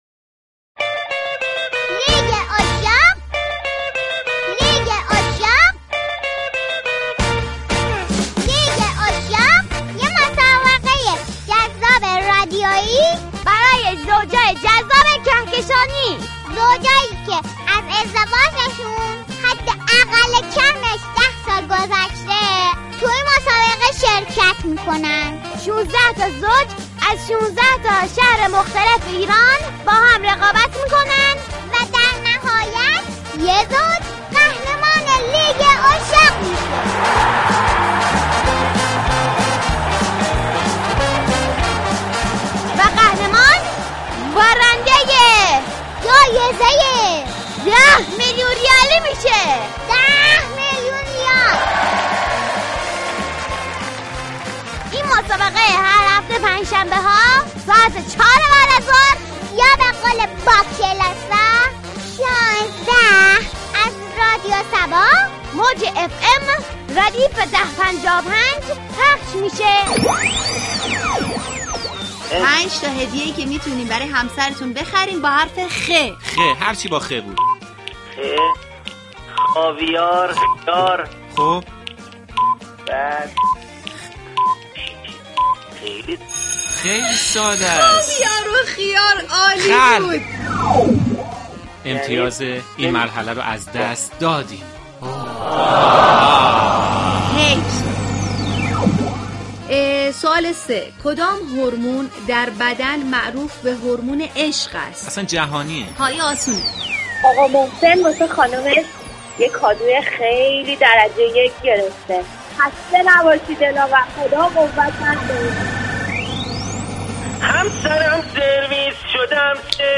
مسابقه پرهیجان «لیگ عشاق » در رادیو صبا با شركت كنندگان گرگان و تهران به مرحله نیمه نهایی رسید.